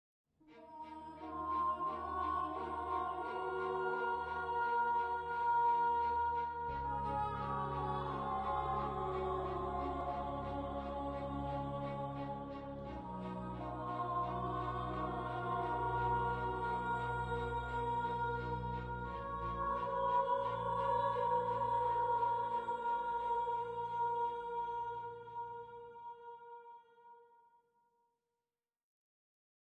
minecraft_dungeon_rumbling.wav